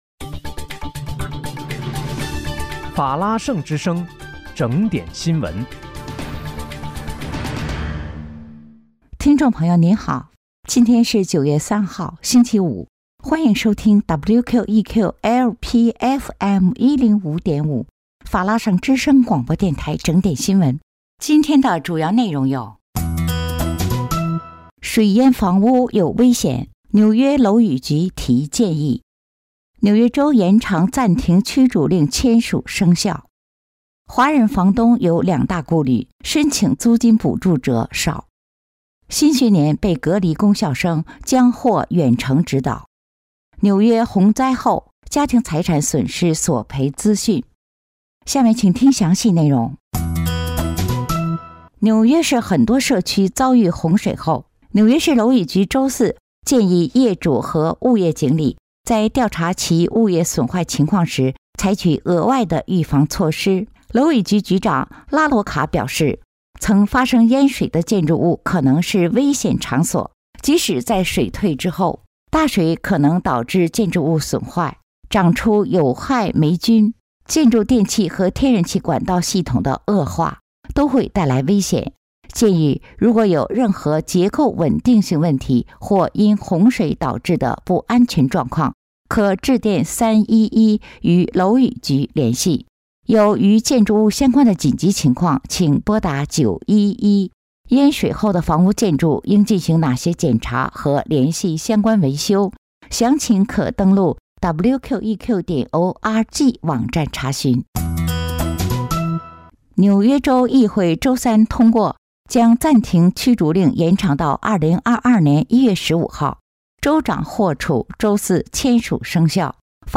9月3日（星期五）纽约整点新闻